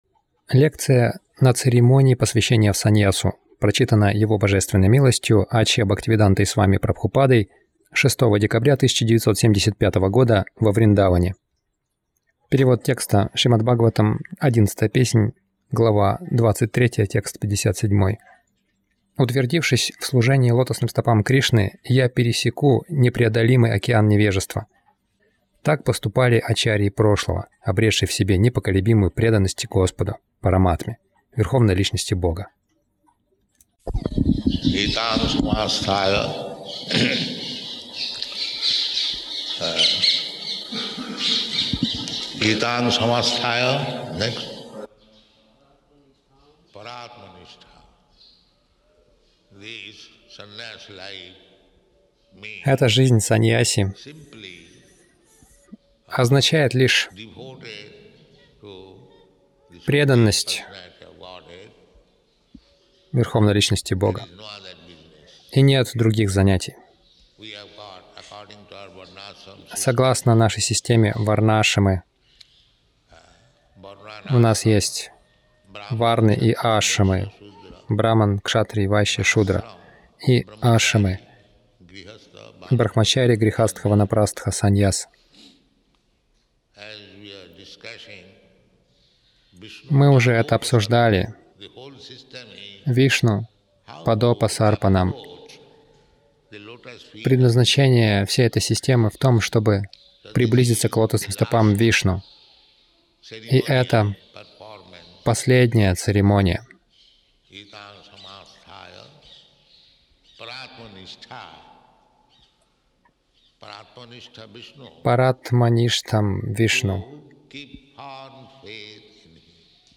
Лекция на санньяса-инициации — Стать гуру не сложно